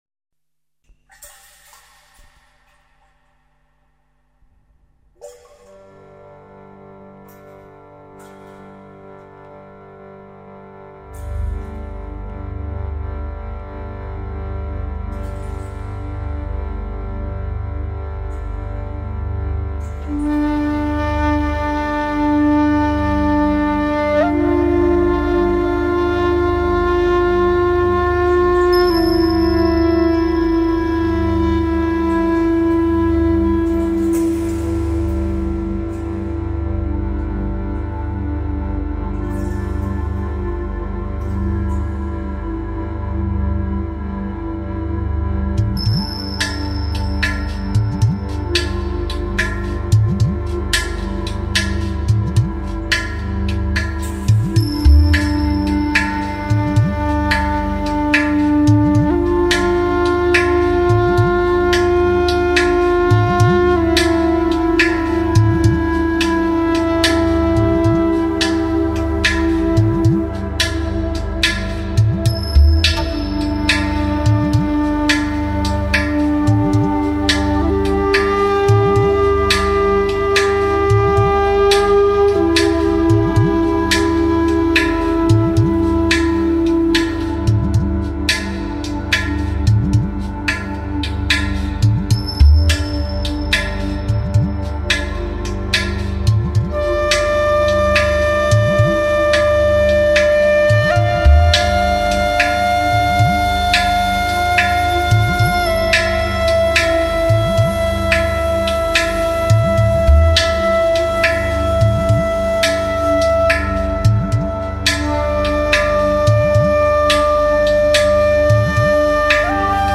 more...deep, ancient atmospheres
clay pot drums, bowl gongs and percussion
flutes and pan pipes
synths and samples